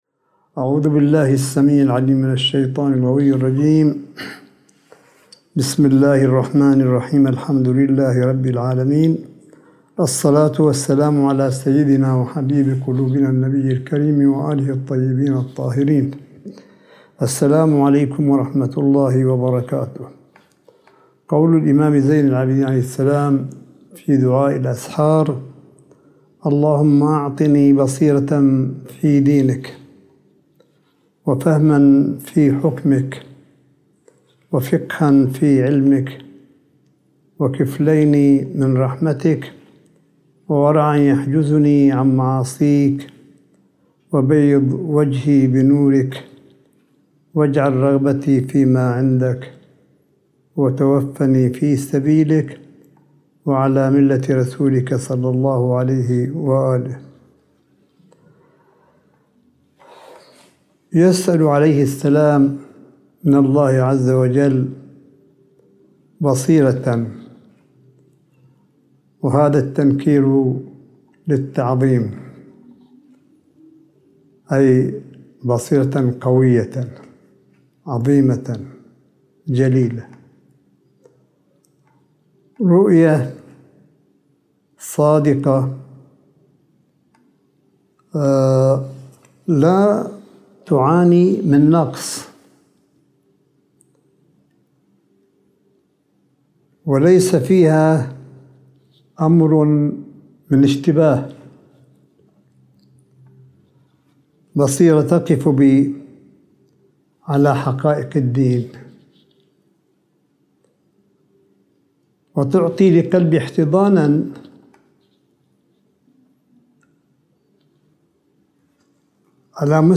ملف صوتي للحديث الرمضاني (25) لسماحة آية الله الشيخ عيسى أحمد قاسم حفظه الله – 26 شهر رمضان 1442 هـ / 08 مايو 2021م